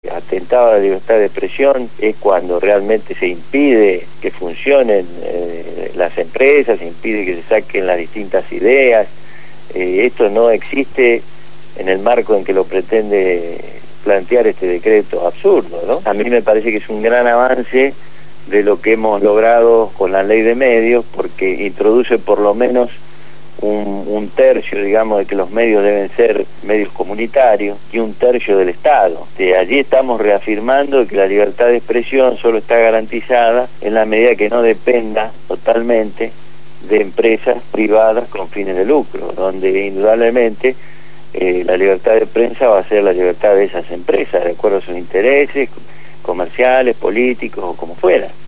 Lo hizo en el programa «Punto de partida» (Lunes a viernes de 7 a 9 de la mañana) por Radio Gráfica FM 89.3